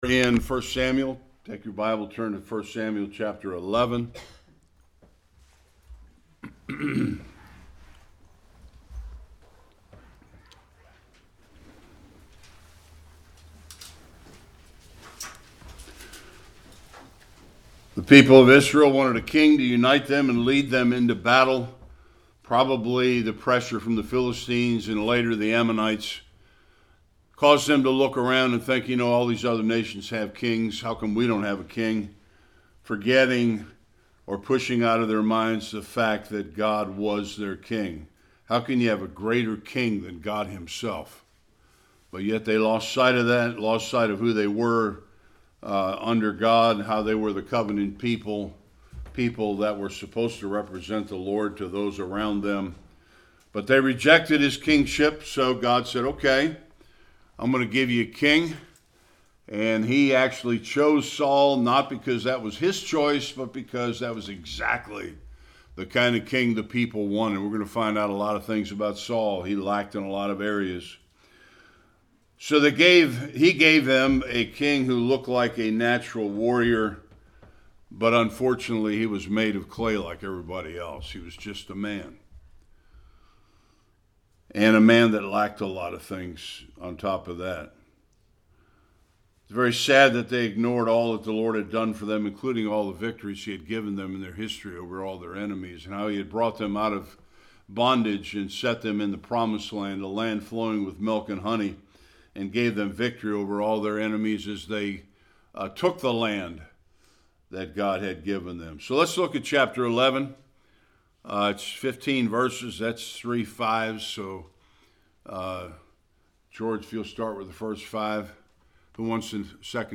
1-10 Service Type: Sunday School King Saul faced his first test as the leader Israel.